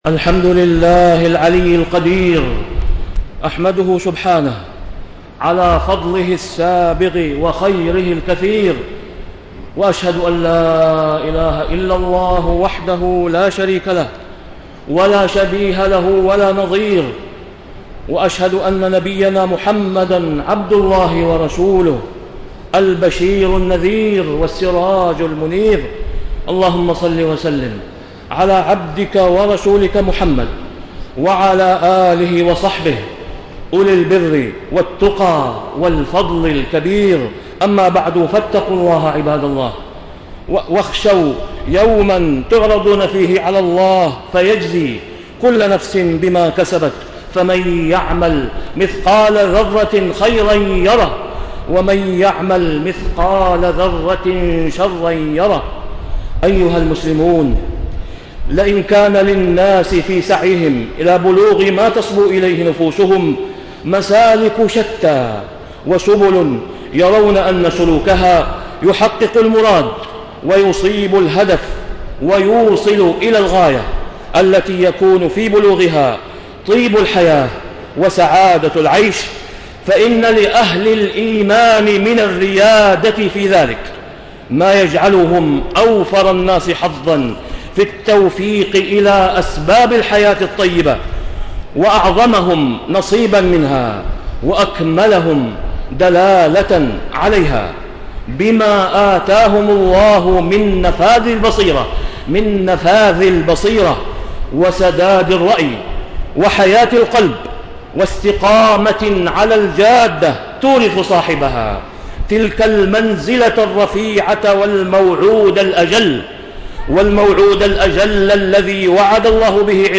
تاريخ النشر ٩ شوال ١٤٣٤ هـ المكان: المسجد الحرام الشيخ: فضيلة الشيخ د. أسامة بن عبدالله خياط فضيلة الشيخ د. أسامة بن عبدالله خياط الاستقامة